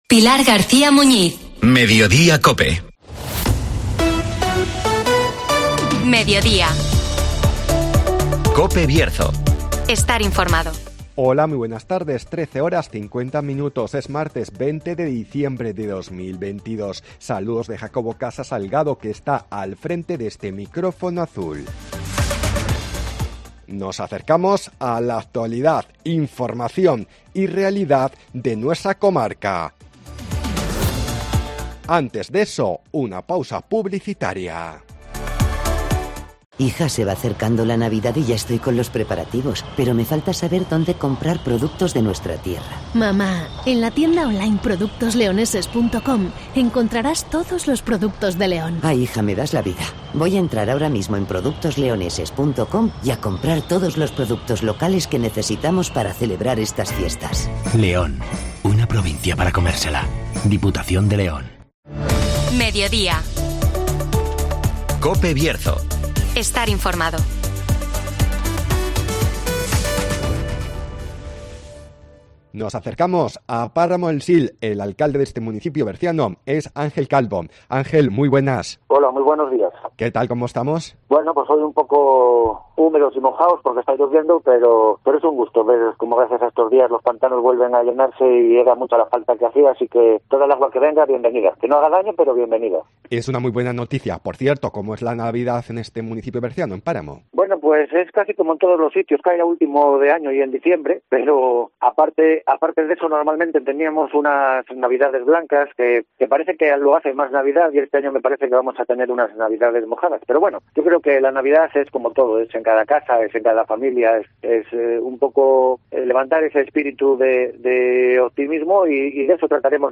Nos acercamos al municipio berciano de Páramo del Sil (Entrevista